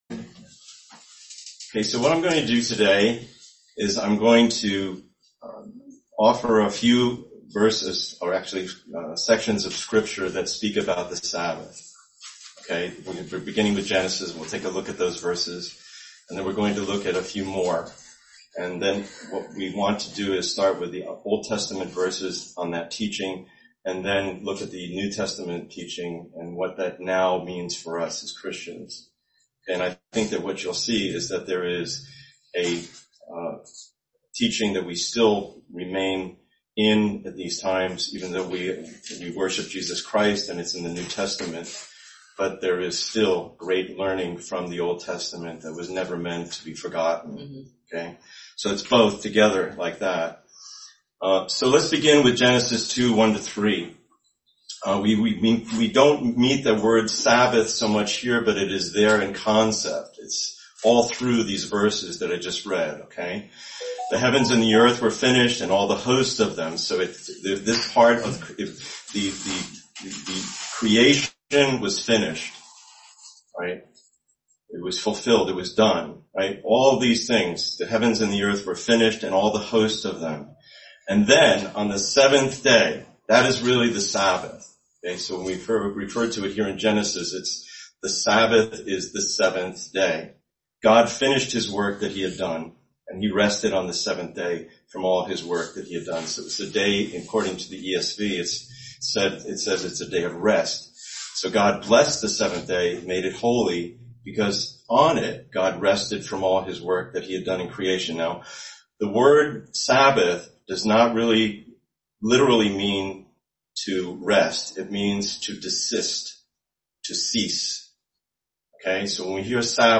Video: Sunday English Worship Video